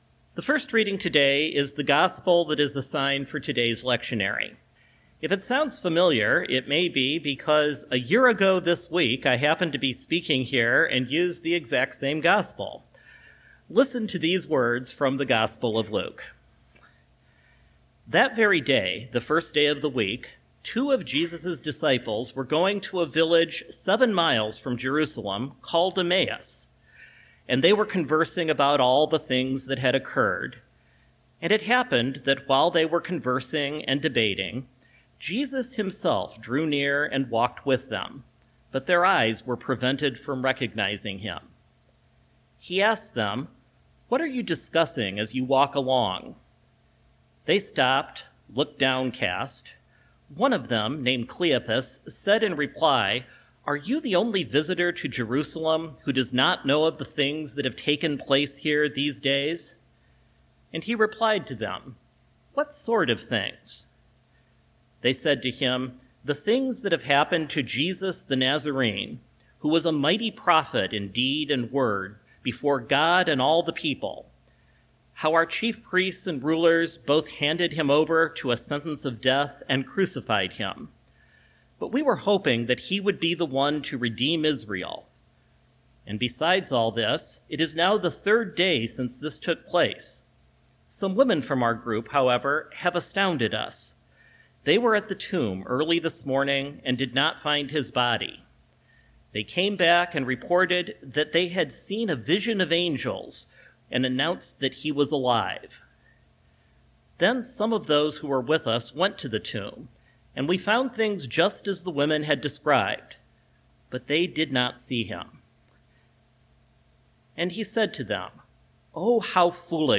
First Congregational Church, Algona, Iowa